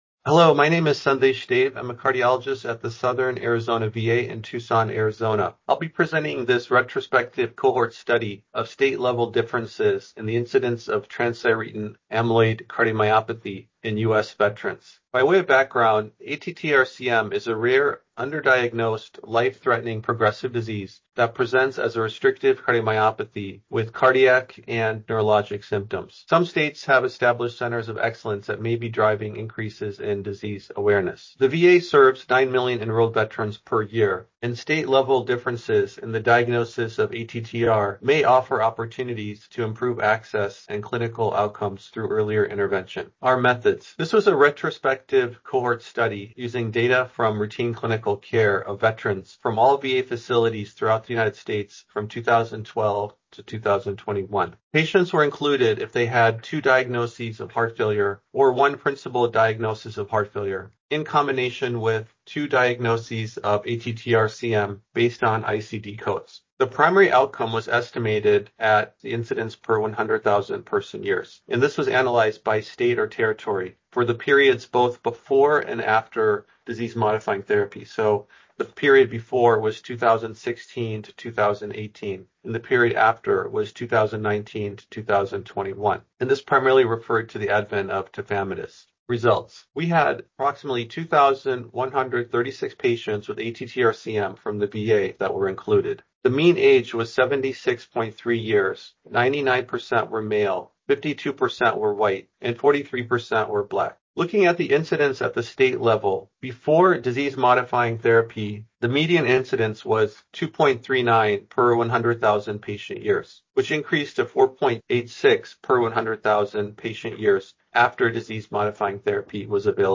Listen to a short talk from the speaker